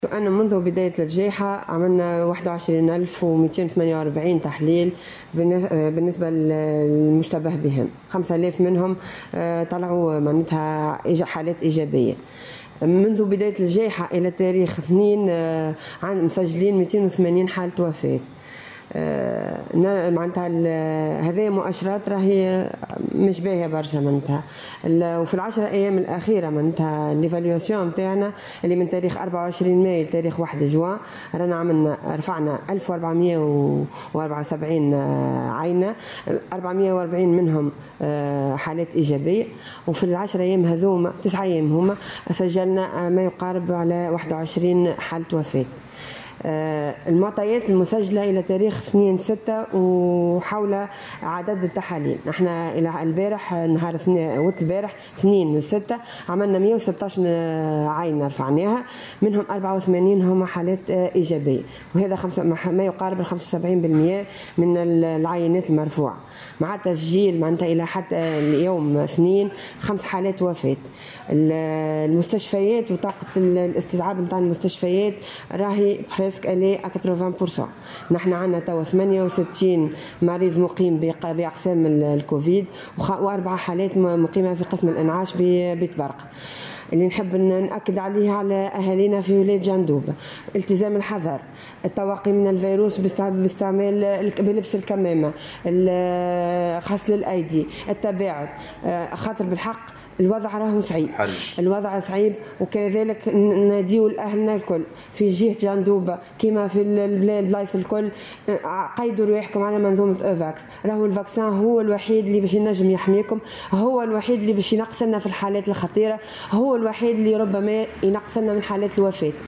وأضافت في تصريح اليوم لمراسل "الجوهرة أف أم" بالجهة، أنه تم خلال الـ9 أيام الأخيرة، تسجيل 21 حالة وفاة، مشيرة إلى أن طاقة استيعاب المؤسسات الصحية بالجهة بلغت 80 بالمائة (68 مريضا يقيمون بأقسام الكوفيد و4 مرضى بقسم الإنعاش).يذكر أن ولاية جندوبة قد سجّلت منذ بداية انتشار الوباء، 282 حالة وفاة و5000 آلاف إصابة بفيروس كورونا.